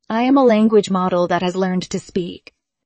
to23oise-tts - (QoL improvements for) a multi-voice TTS system trained with an emphasis on quality